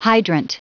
Prononciation du mot hydrant en anglais (fichier audio)
Prononciation du mot : hydrant
hydrant.wav